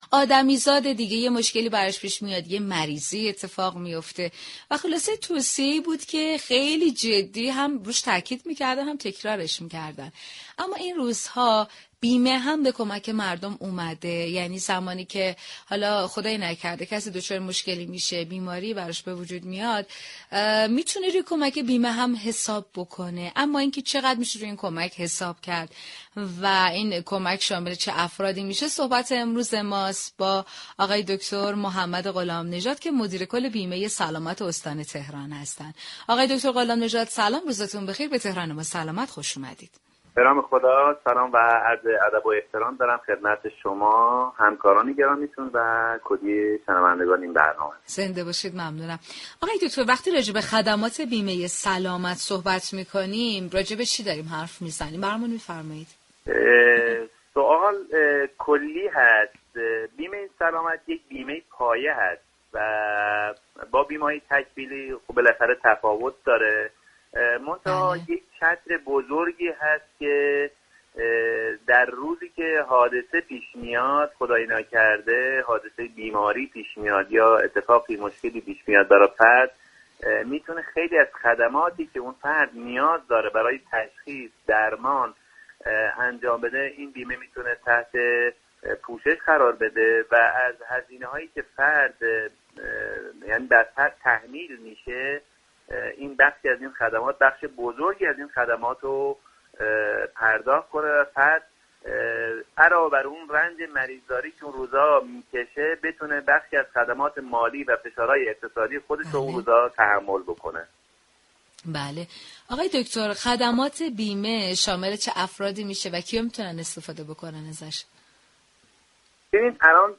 بیمه سلامت با بیمه تكمیلی فرق دارد به گزارش پایگاه اطلاع رسانی رادیو تهران، محمد غلام نژاد مدیركل بیمه سلامت استان تهران در گفت و گو با «تهران ما سلامت» رادیو تهران اظهار داشت: بیمه سلامت یك بیمه پایه است و با بیمه‌های تكمیلی متفاوت است.